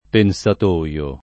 pensatoio [ pen S at 1L o ] s. m.